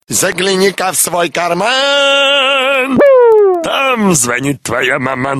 Реалтон на мамочку